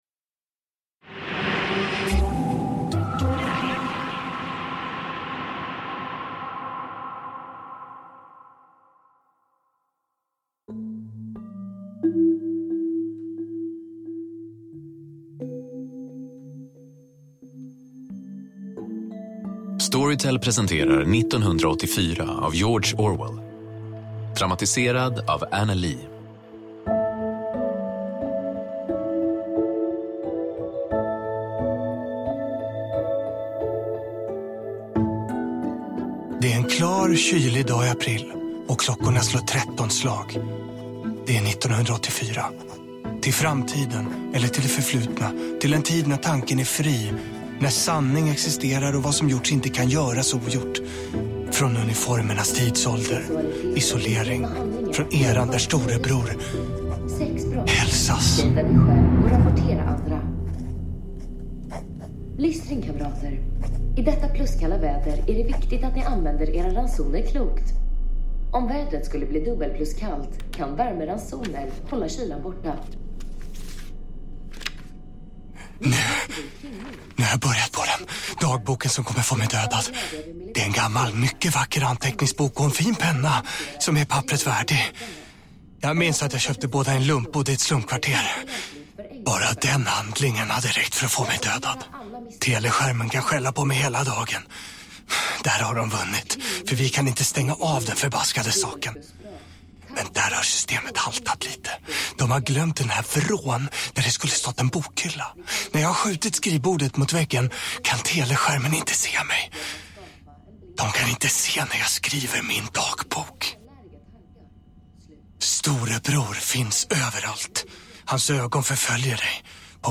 1984 – Ljudbok – Laddas ner
En nyskapande ljudupplevelse där du får höra George Orwells ikoniska klassiker som du aldrig hört den förut!